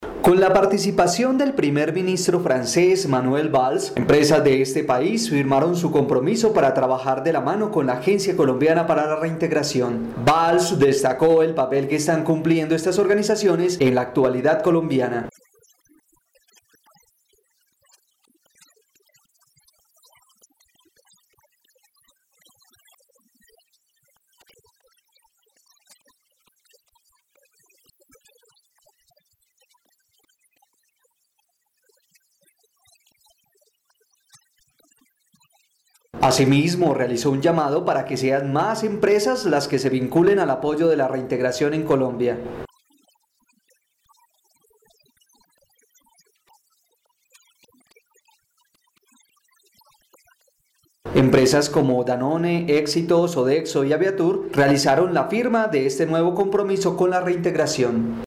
AUDIO: Declaraciones del primer ministro de Francia, Manuel Valls